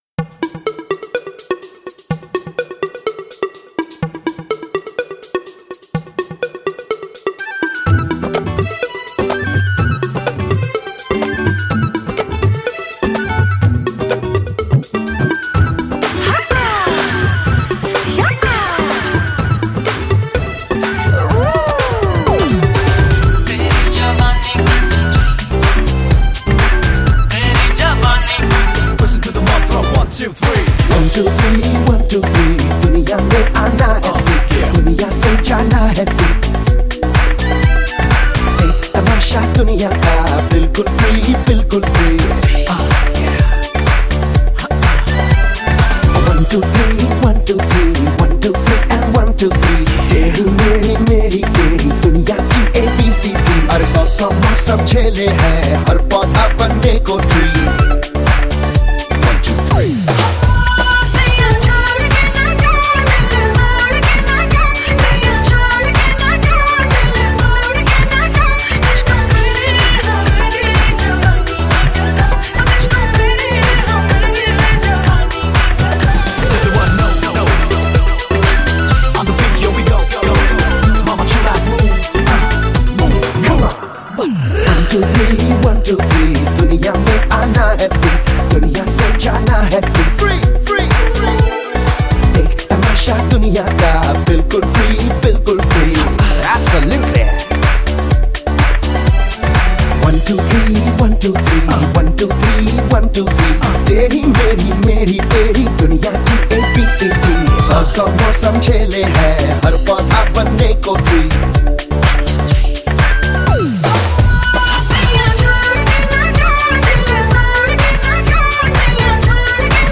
Bollywood MP3 Songs 2008